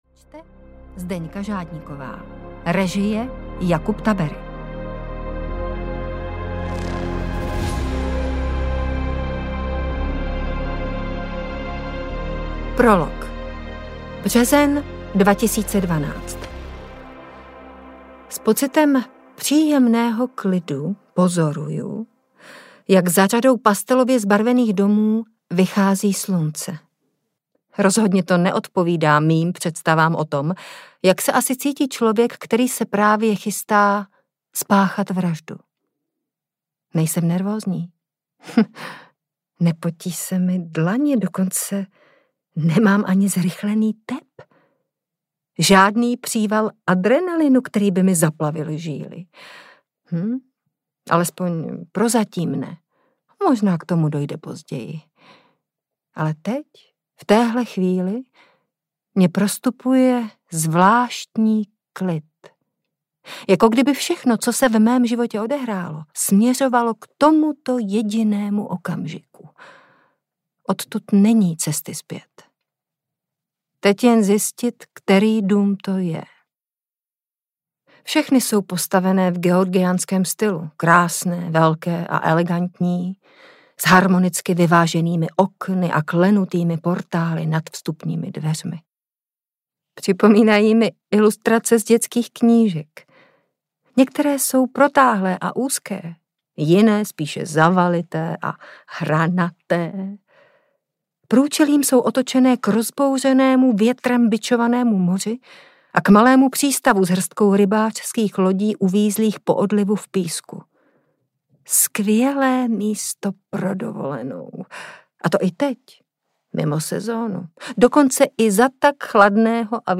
Odešla do tmy audiokniha
Ukázka z knihy
• InterpretZdeňka Žádníková Volencová